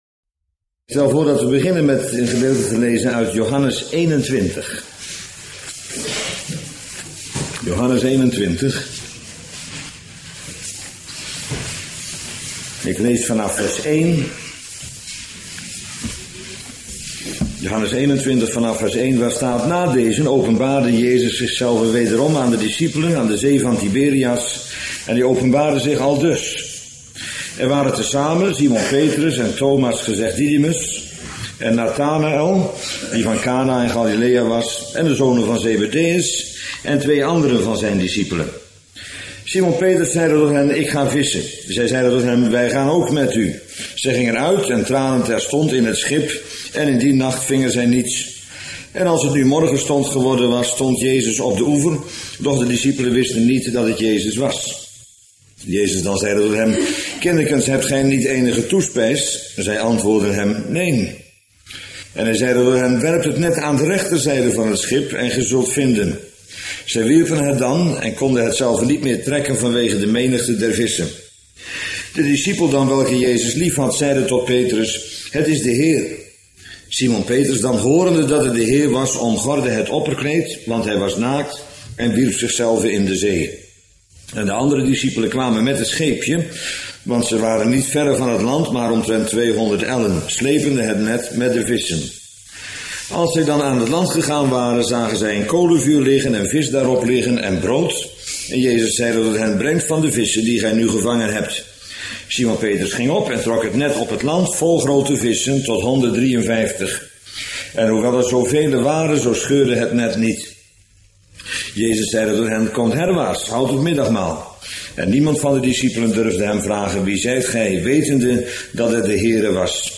Bijbelstudie lezingen